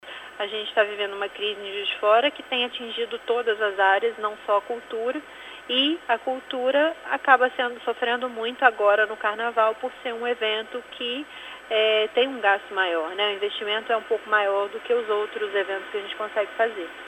Bancada “da Bala” comemora decreto de posse de arma. De Brasília